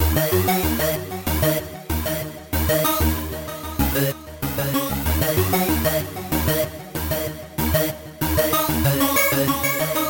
地球减去人类主角合成器 95bpm
Tag: 95 bpm Trap Loops Synth Loops 1.70 MB wav Key : D